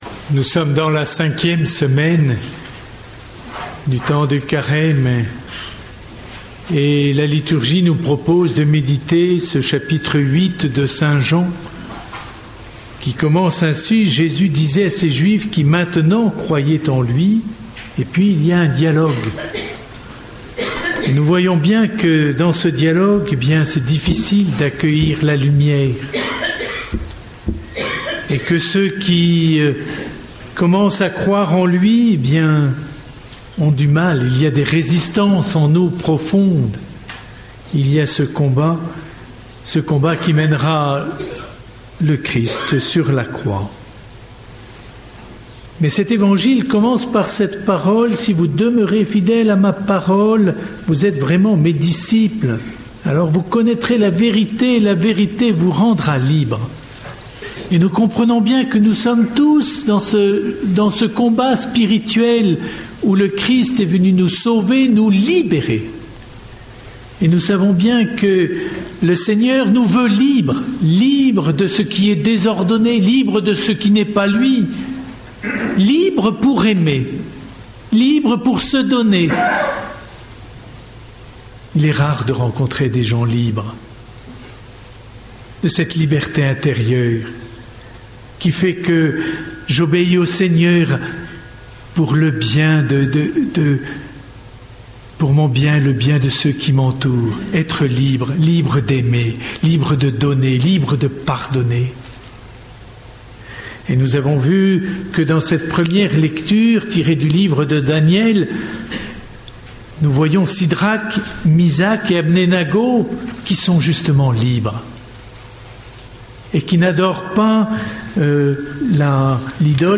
Session des retrait�s - du 25 au 31 mars 2012 Carrefour Enregistr� le 27 mars 2012.